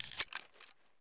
knife_draw.ogg